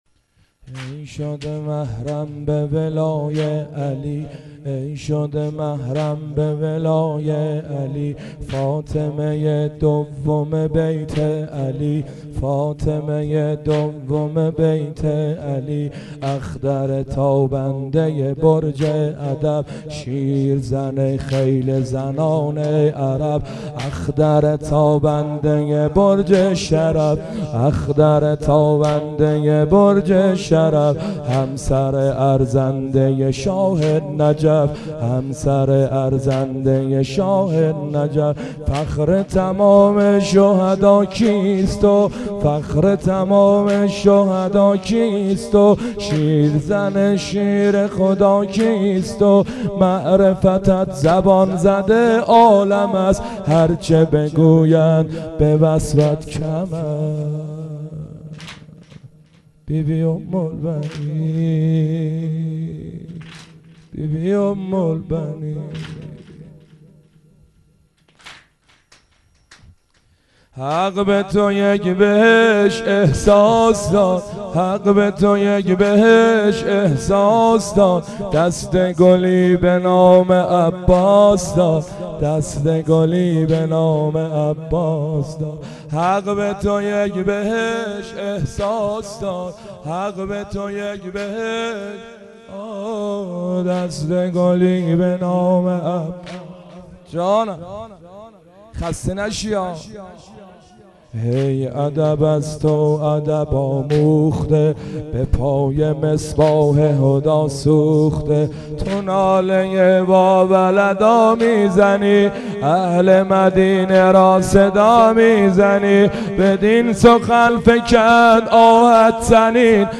مداحی
بمناسبت وفات جناب ام البنین سلام الله علیها